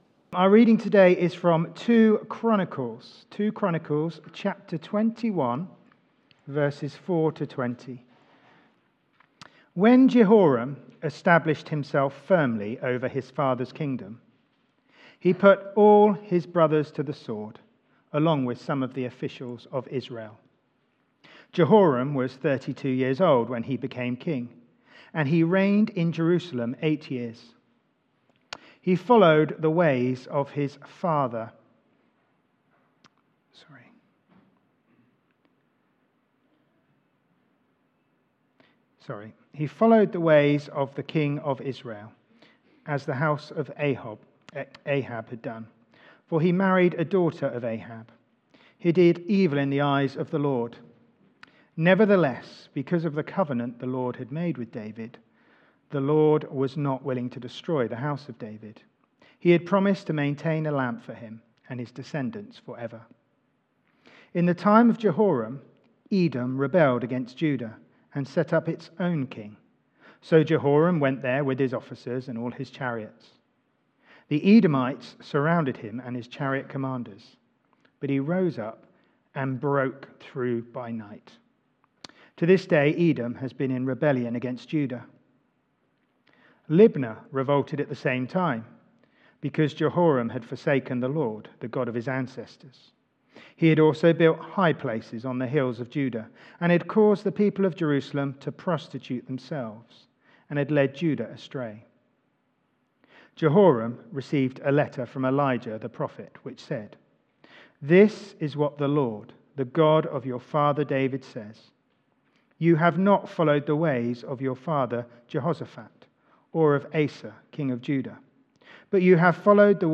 Media for Sunday Service on Sun 14th Jul 2024 10:00
Theme: Sermon